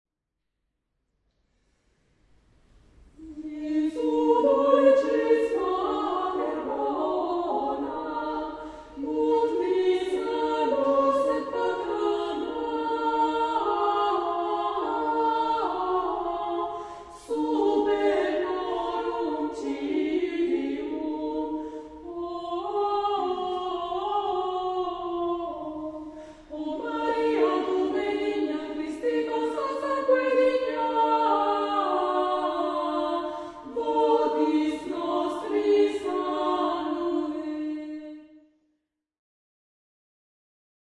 monodia e polifonia medievali
VI B 24, XVI sec., cantio- mottetto, ff. 138v - 139v